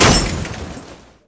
damage50_2.wav